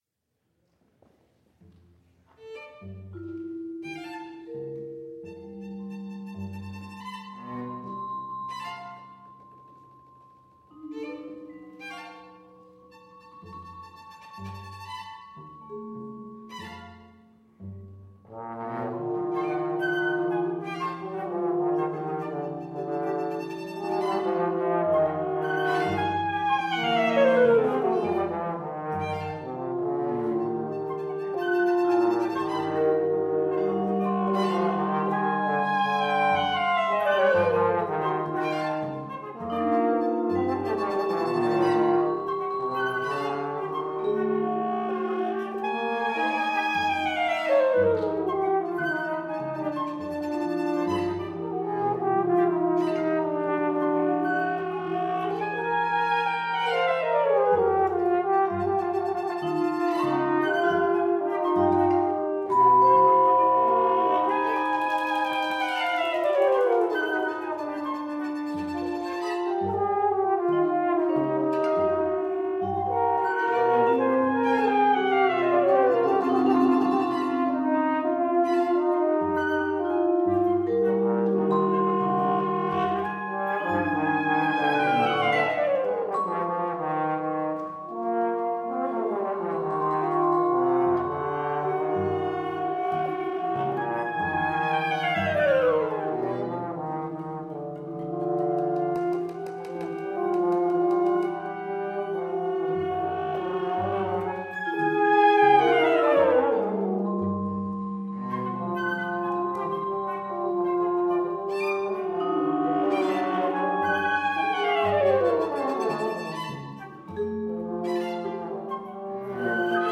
for six musicians (short: 5', long: 9')
Live Performance: